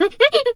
pgs/Assets/Audio/Animal_Impersonations/hyena_laugh_short_07.wav at master
hyena_laugh_short_07.wav